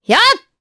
Priscilla-Vox_Attack2_jp.wav